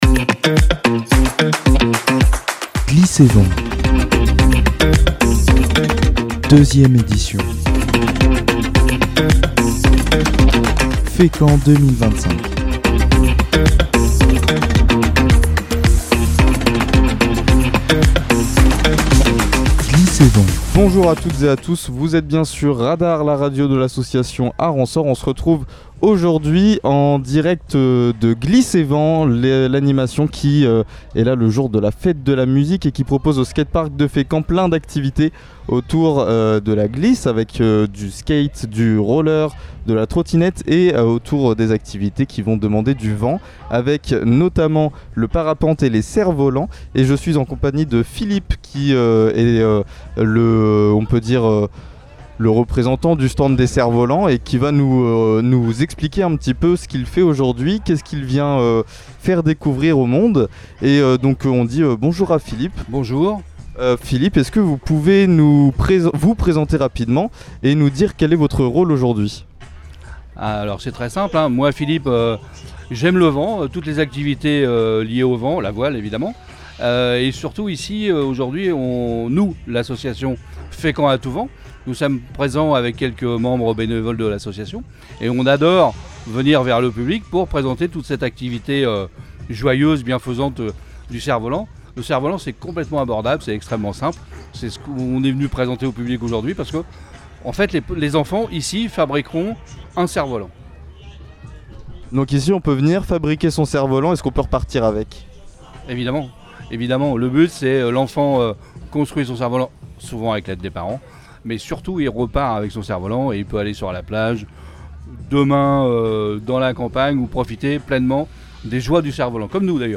Le studio mobil est une caravane entièrement équipée pour produire des émissions couvrant différents événements. On y reçoit les acteurs et participants de ces manifestations pour les interviewer en direct (quand les connexions sont possibles) ou en conditions de direct, ce qui donne de nombreux podcasts à retrouver ici.